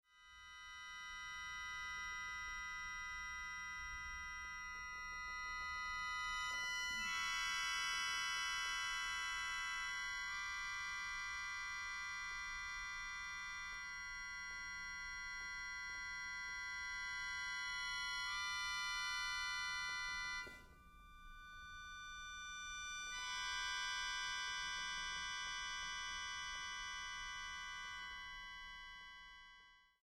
acordeonista
actrice y cantante